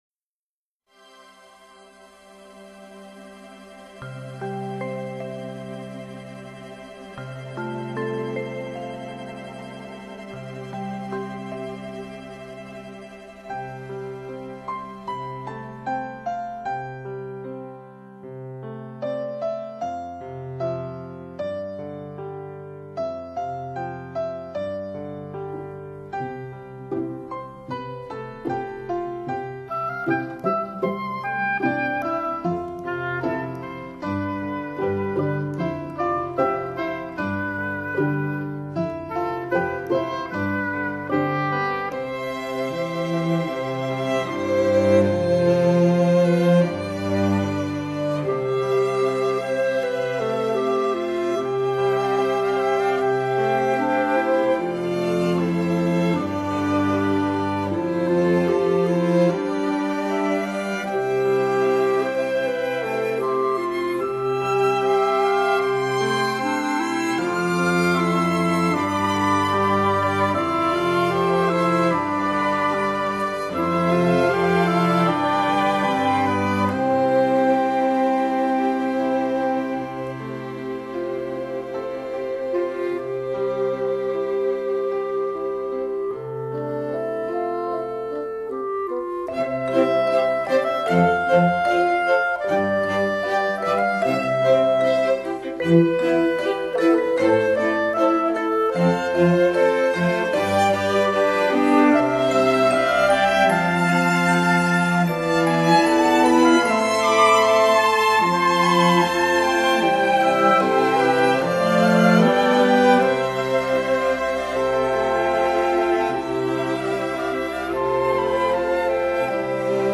為台北寫的音樂，西風中有老調，時尚中有迷離，那是重疊了過去繁華的現代節奏。